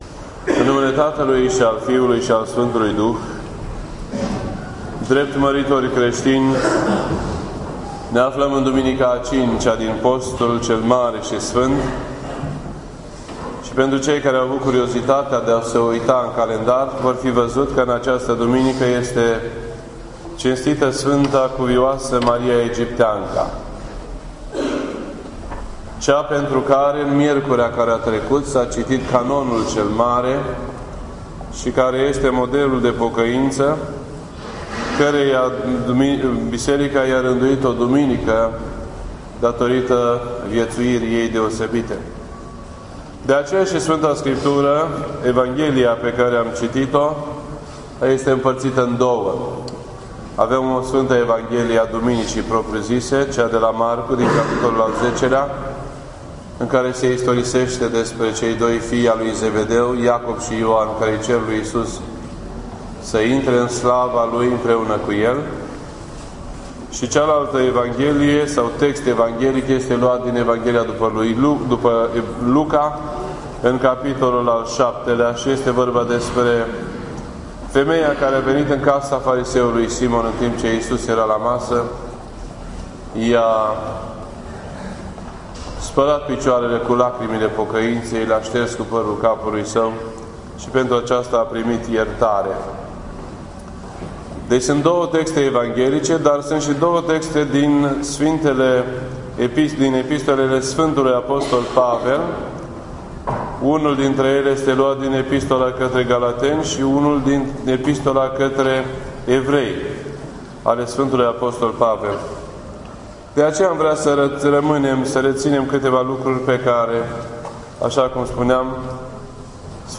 This entry was posted on Sunday, April 6th, 2014 at 12:14 PM and is filed under Predici ortodoxe in format audio.